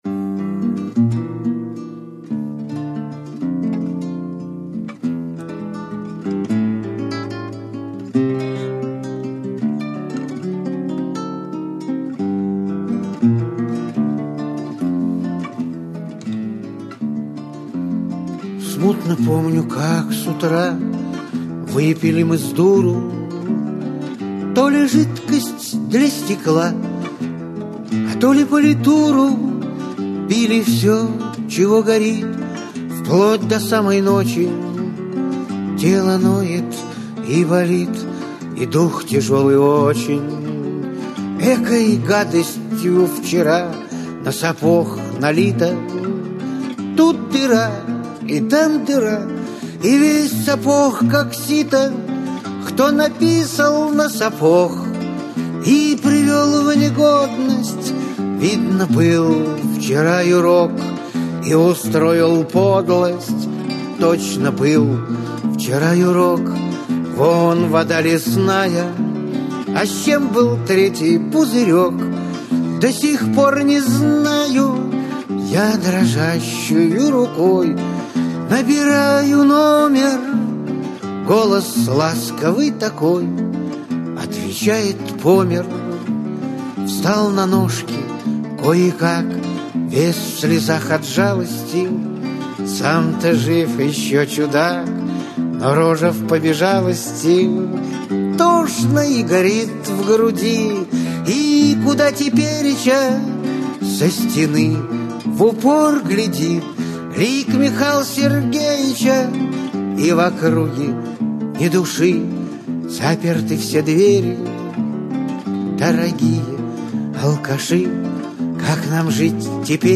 Категория: Барды